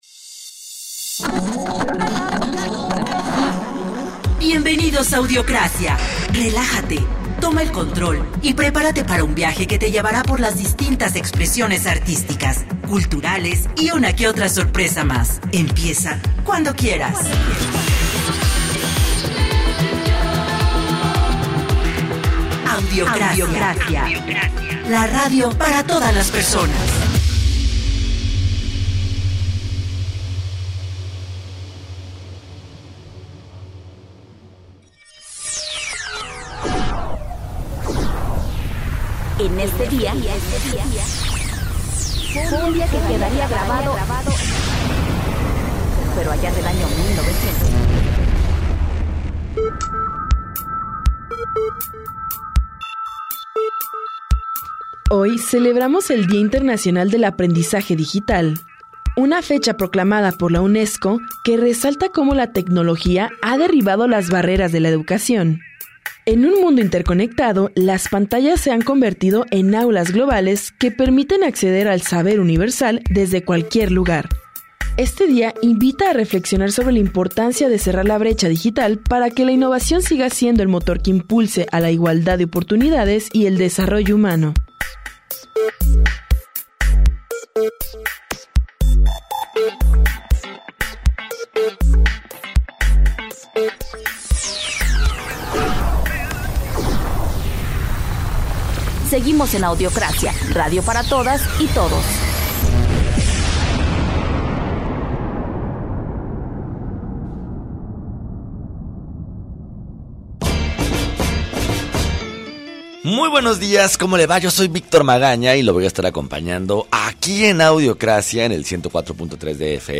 Arranca la conversación… y la música no se queda atrás.